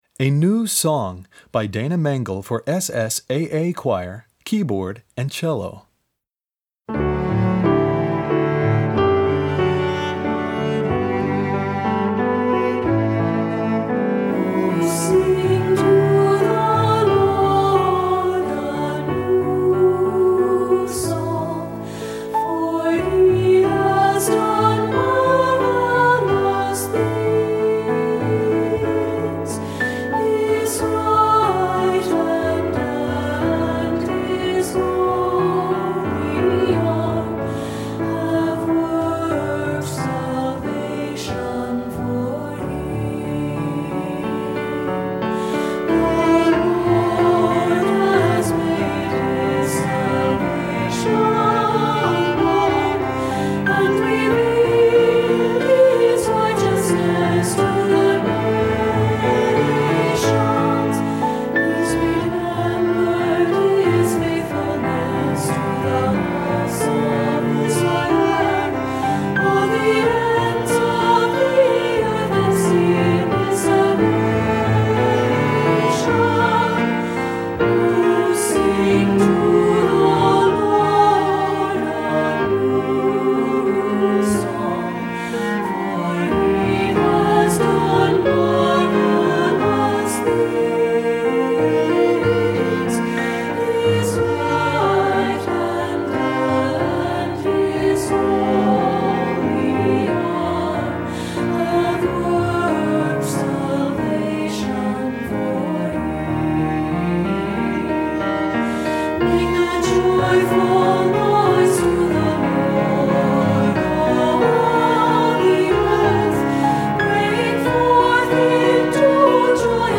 Voicing: SSAA